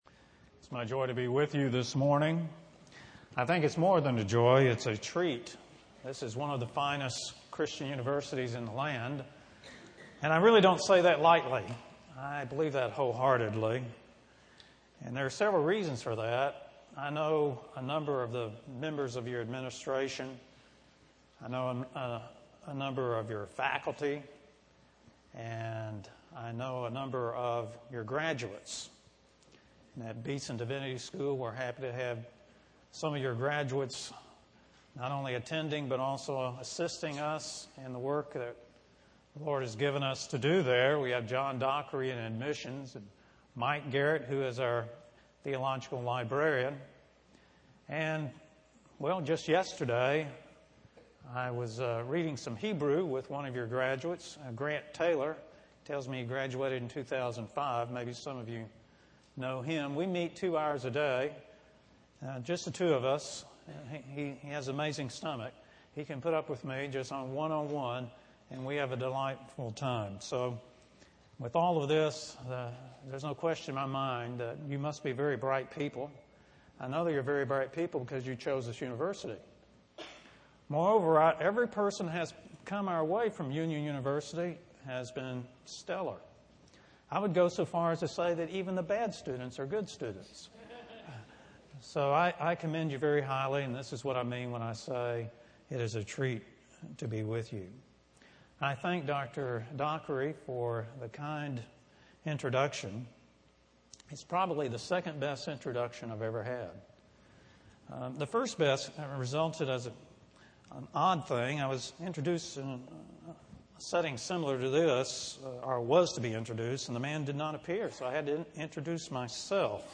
Ryan Center Conference Chapel